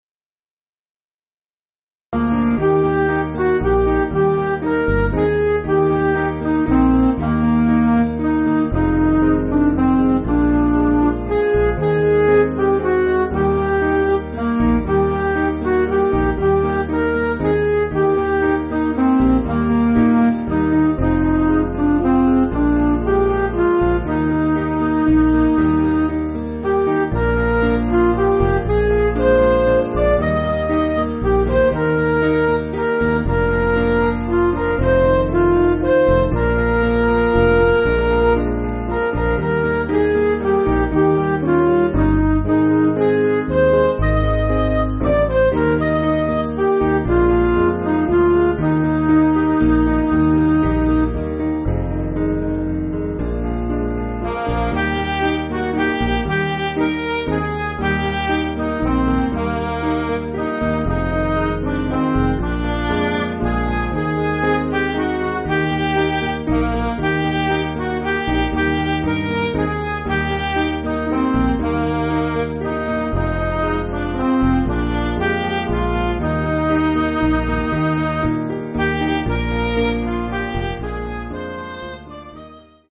Piano & Instrumental
(CM)   3/Eb